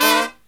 FALL HIT06-L.wav